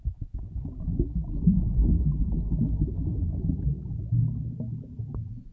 bubbles1.wav